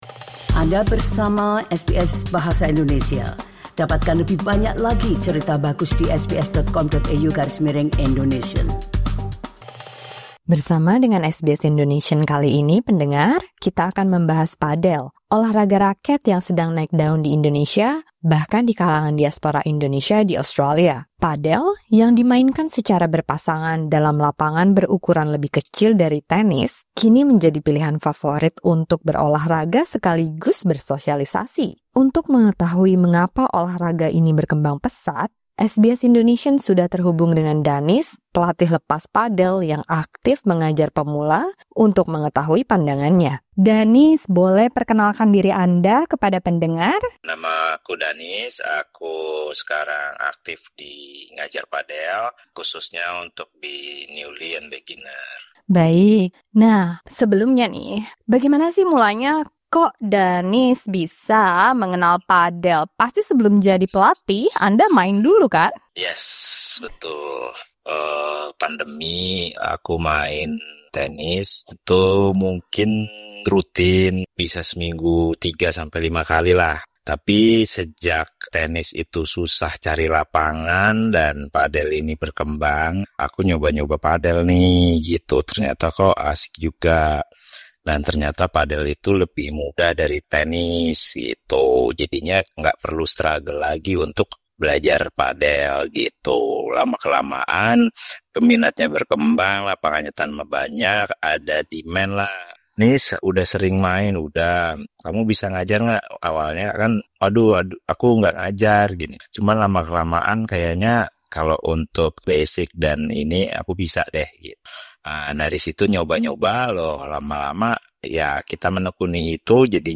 Simak perbincangan SBS Indonesian